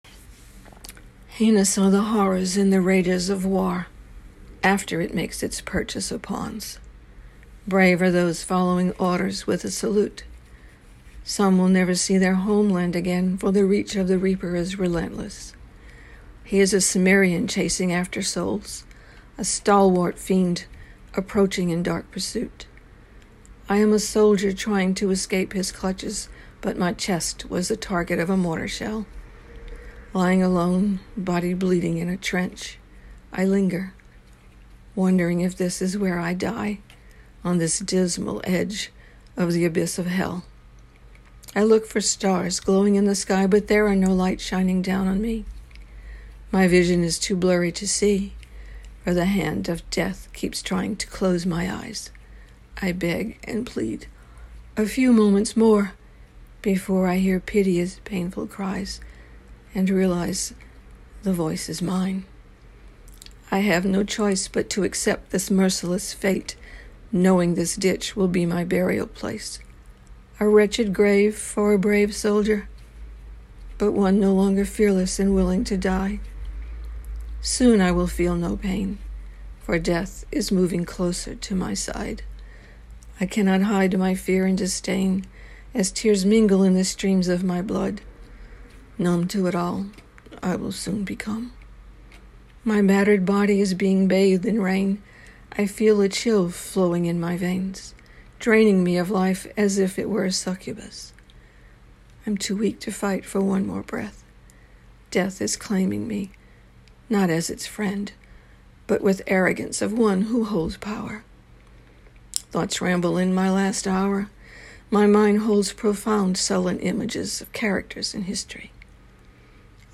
You sound so gentle and feminine!